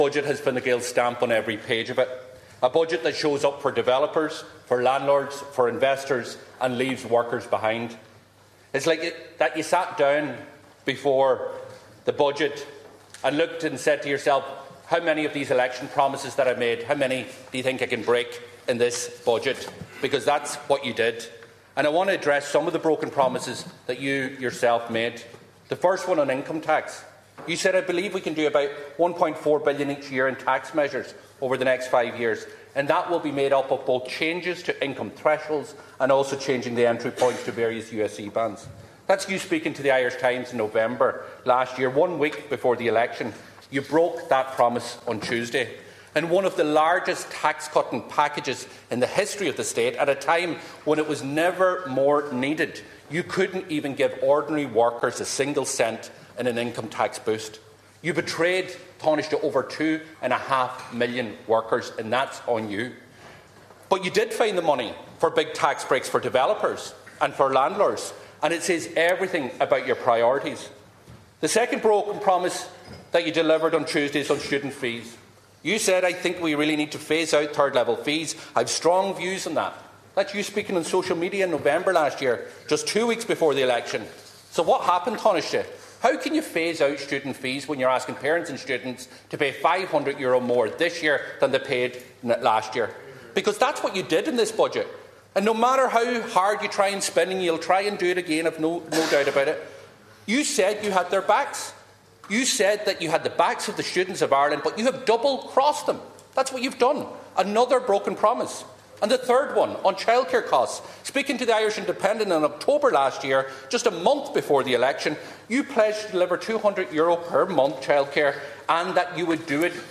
The Dáil has been told that the government has broken promise after promise, and that’s evidenced in this week’s budget.
Deputy Pearse Doherty said despite promises of tax cuts, there were no breaks for workers in this budget.
Tánaiste Simon Harris hit back, saying the government has always made it clear that spending on public services and infrastructure would be prioritised.